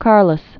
(kärləs, -lōs), Don Count of Molina. 1788-1855.